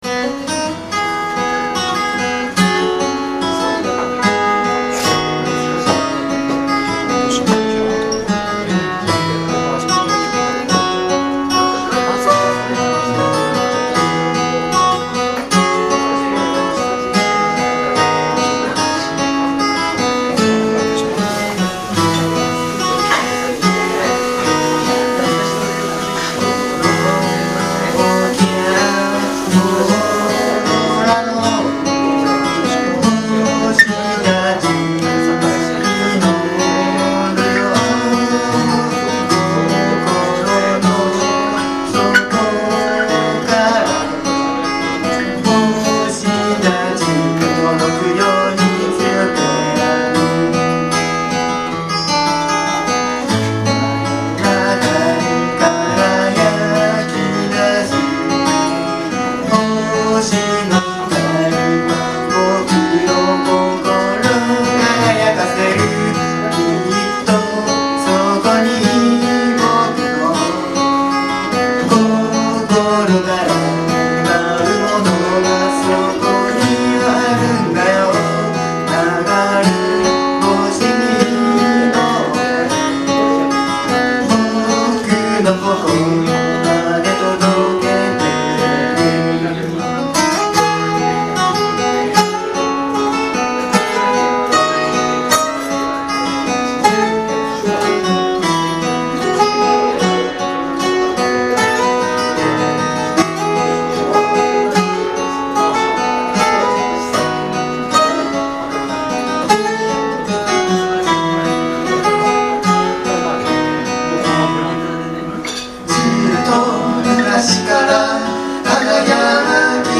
Bluegrass style Folk group
Key of E→F#
しっとりとした曲調と歌詞のイメージから、最近はエンディングテーマにもなっています。
企画名: アコースティックライブ
録音場所: ふじみ野ふぃるもあ
リードボーカル、ギター
コーラス、リードギター